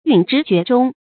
允执厥中 yǔn zhí jué zhōng 成语解释 谓言行符合不偏不倚的中正之道。